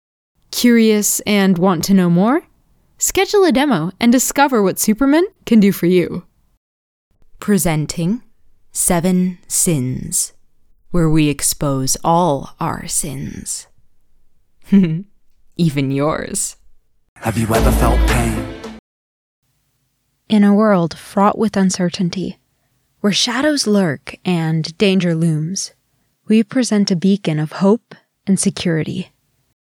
A short narration and advertisement reel for commercial projects.
Demo-reel-narration-and-advert.mp3